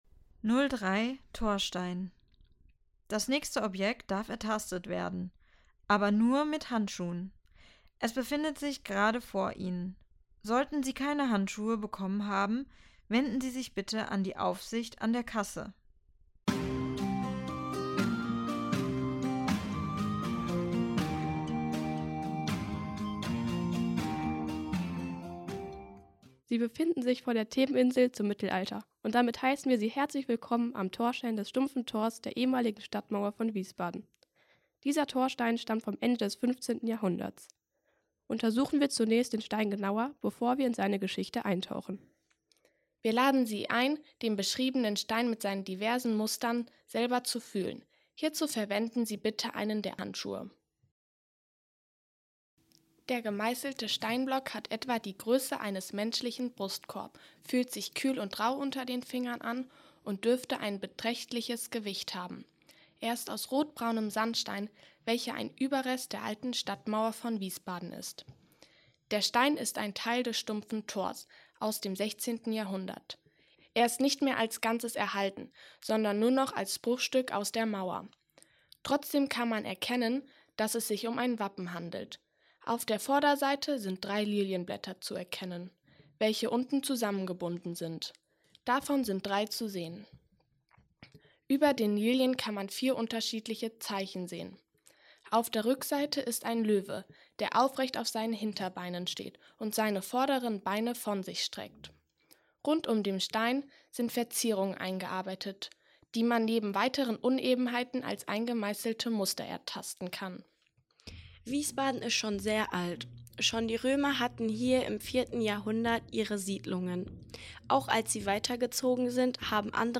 Audioguide - Station 3 Torstein